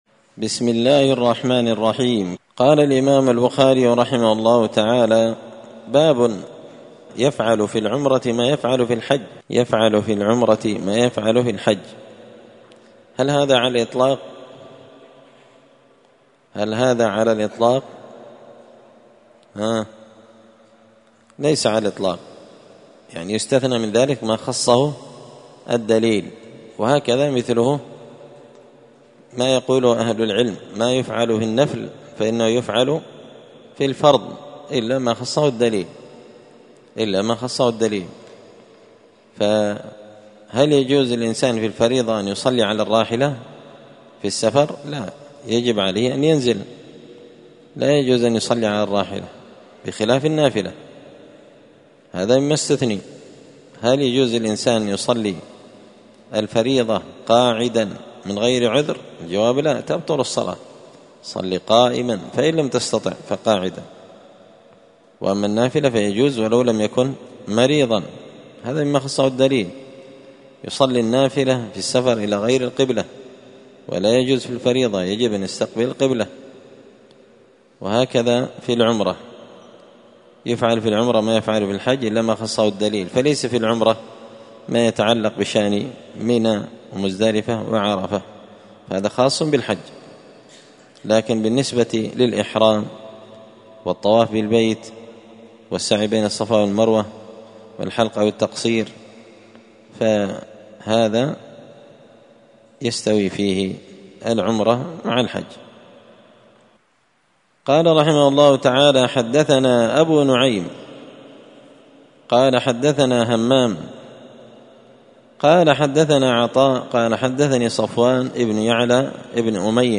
السبت 11 محرم 1445 هــــ | الدروس، شرح صحيح البخاري، كتاب العمرة | شارك بتعليقك | 14 المشاهدات
مسجد الفرقان قشن المهرة اليمن